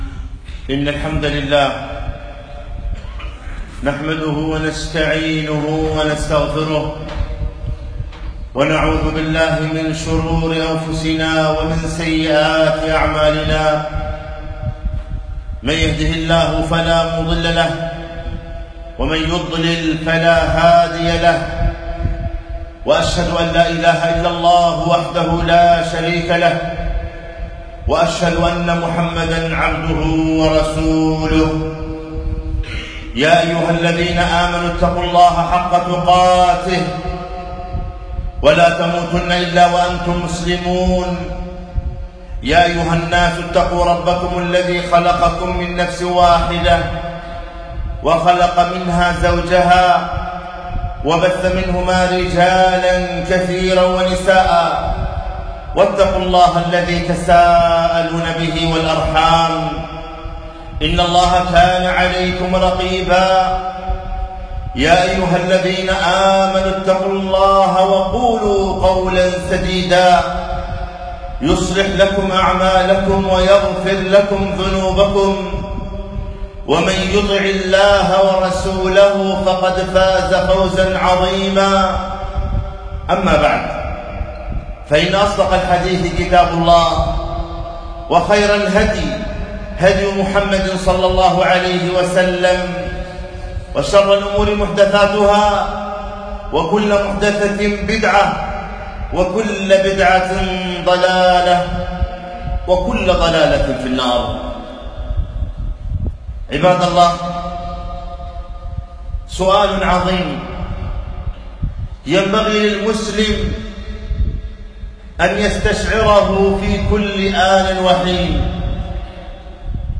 خطبة - لماذا خلقك الله ؟